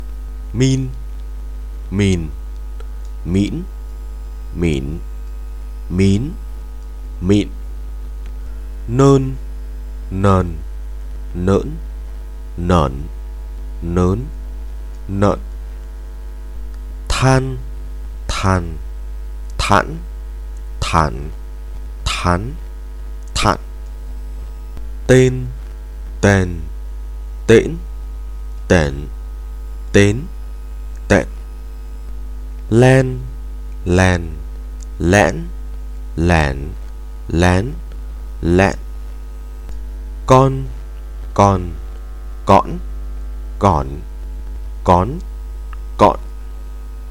9. 有关韵母声调练习（C）
注意：这里的练习纯粹是为了训练声调发音。有些发音仅用于练习，不具有实际意义。